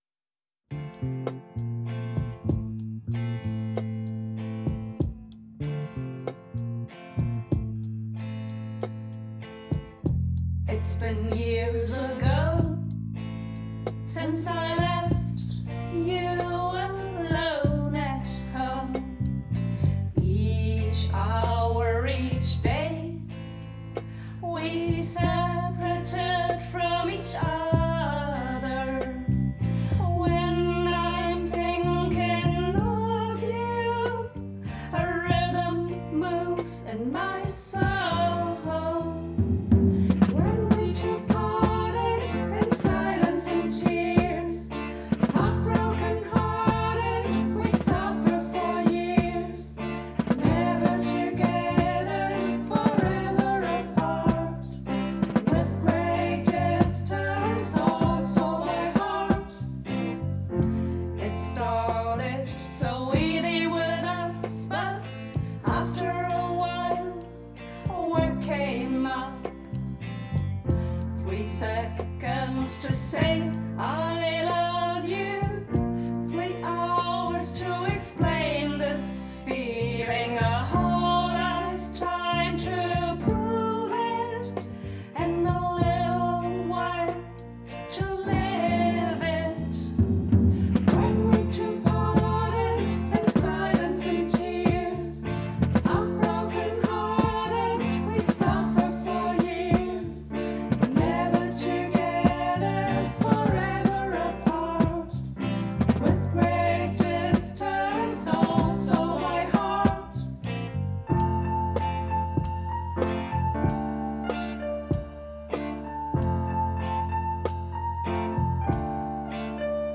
Diese 2 Songs haben wir im Tonstudio eingespielt: